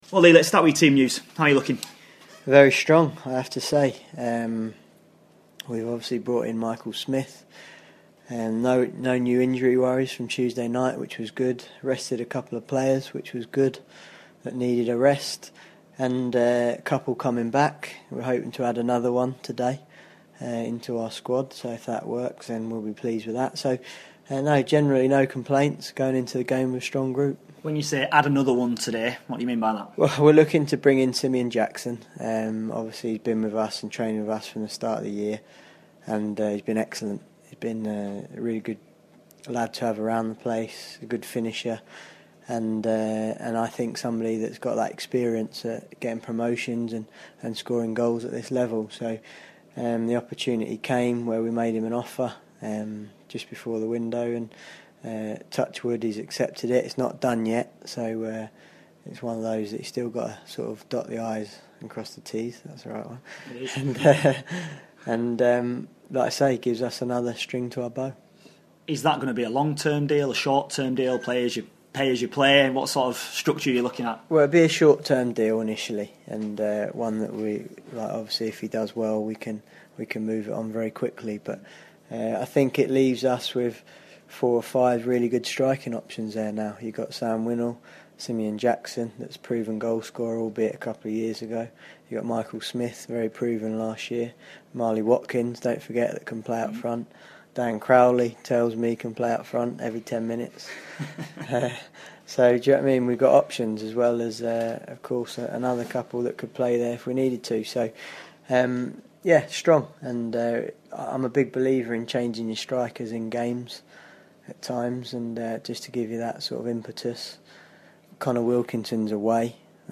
INTERVIEW: Barnsley boss Lee Johnson ahead of the Reds clash with Shrewsbury